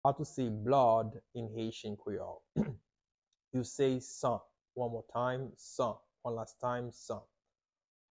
How to say "Blood" in Haitian Creole - "San" pronunciation by a native Haitian Creole teacher
“San” Pronunciation in Haitian Creole by a native Haitian can be heard in the audio here or in the video below:
How-to-say-Blood-in-Haitian-Creole-San-pronunciation-by-a-native-Haitian-Creole-teacher.mp3